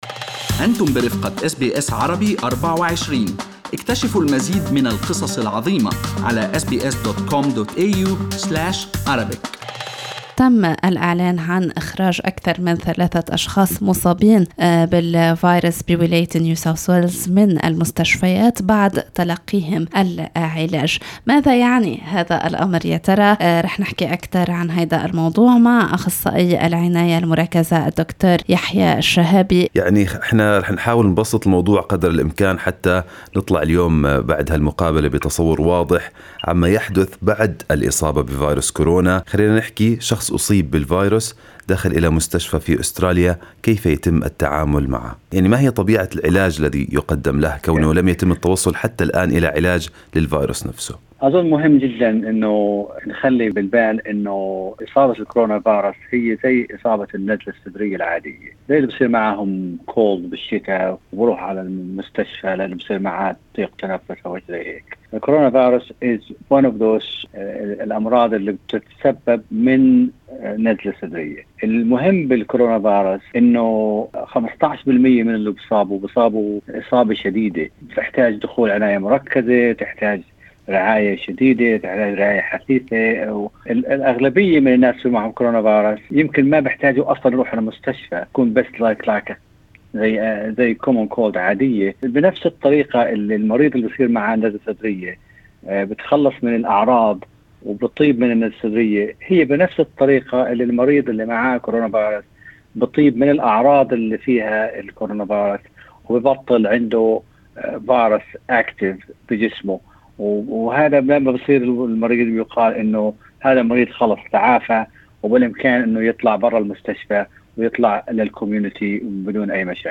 طبيب متخصص في العناية المركزة يشرح الطرق المتبعة في علاج مرضى فيروس كورونا ويقدم نصائح للتعامل مع الأزمة.